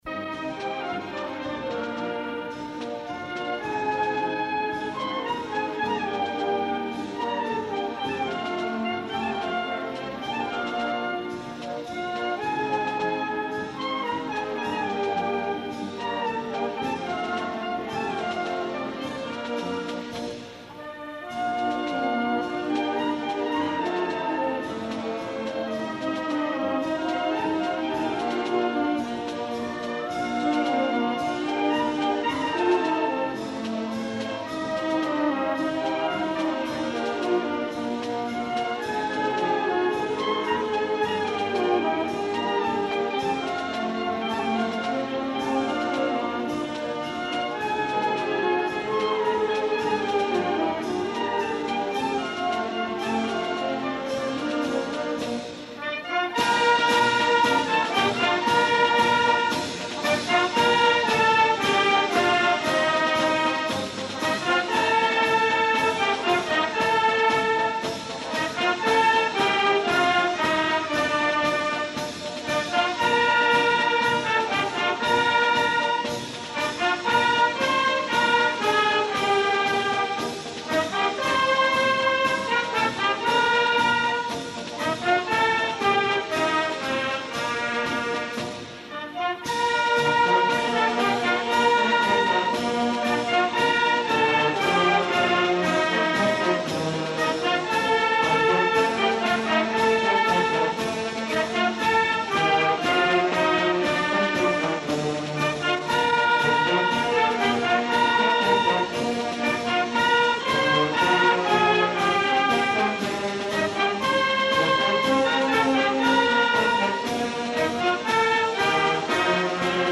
Concert sa fira 1988. Esglesia parroquial de Porreres Nostra Senyora de la Consolació.